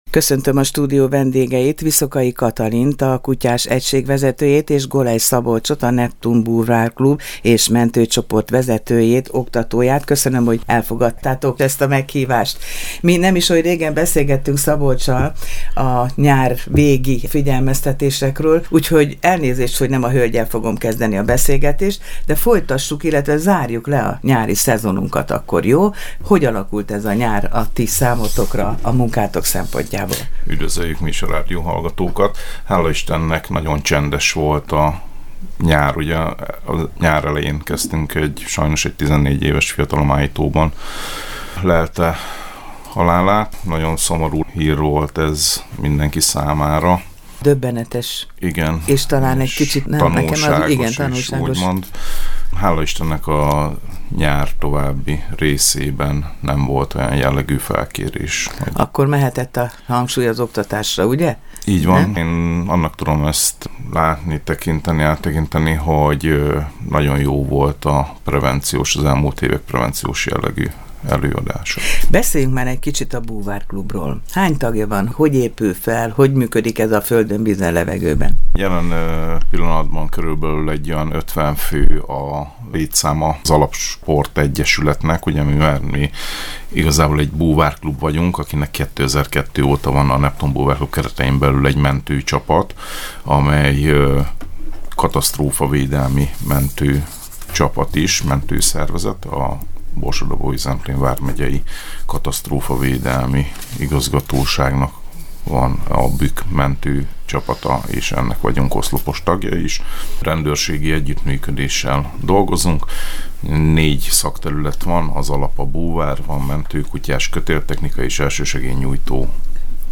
Egészség- és sportrádió Miskolc - FM 103